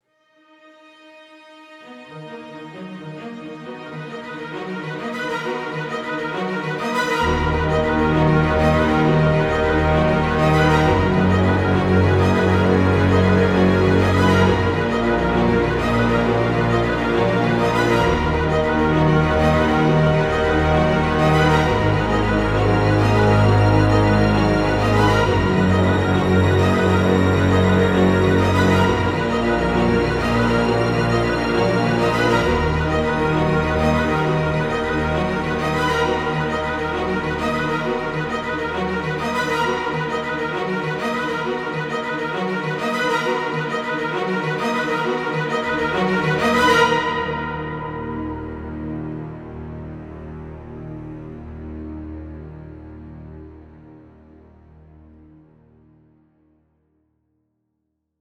Suspense cinematic theme.